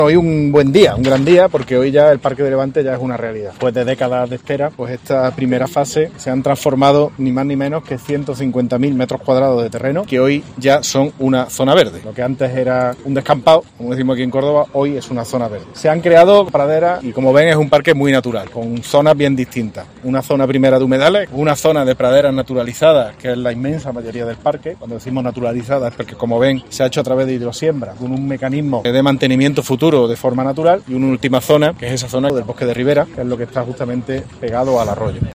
Escucha al alcalde, José María Bellido, sobre el Paque de Levante